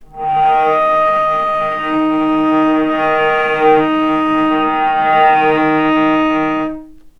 vc_sp-D#3-mf.AIF